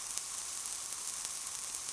SprayNeedsShaking.ogg